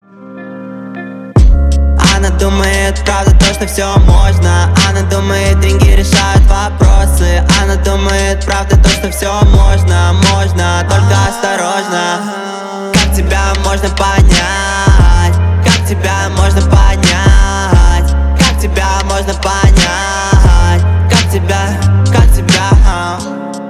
• Качество: 320, Stereo
лирика
спокойные